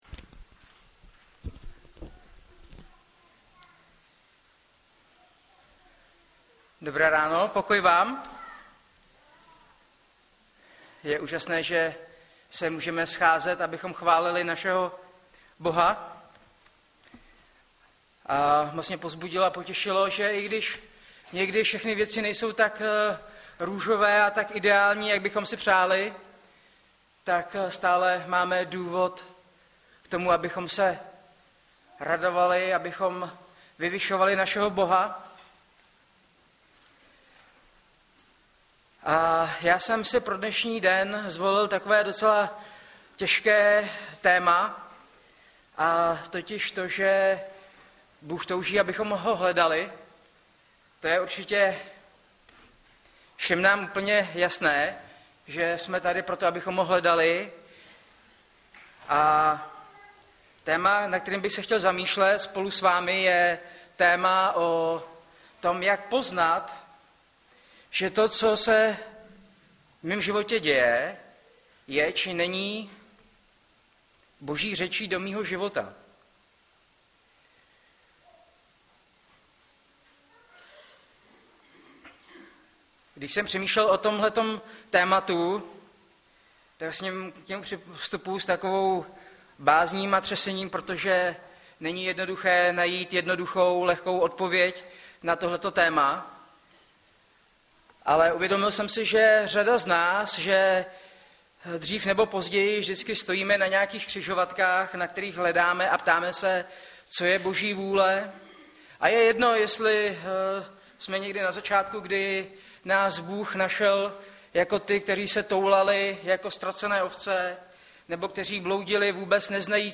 - Jan 10,1-10 Audiozáznam kázání si můžete také uložit do PC na tomto odkazu.